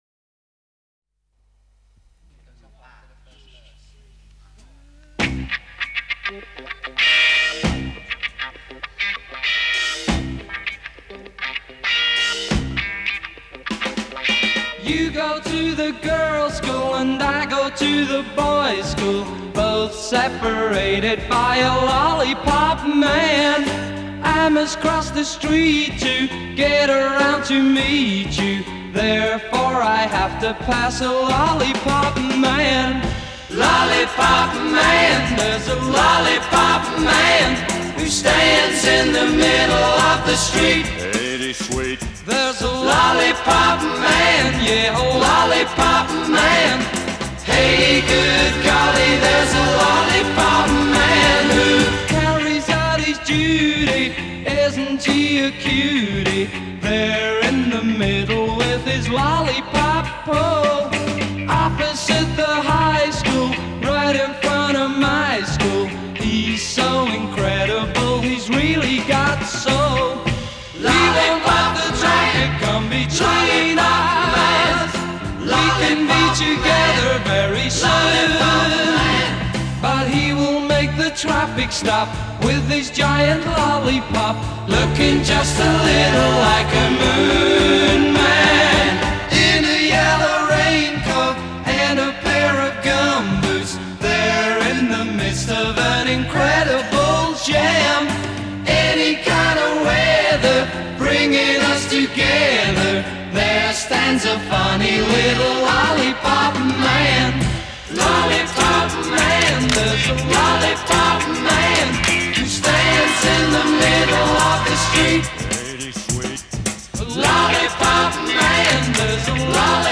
Genre: Classic Rock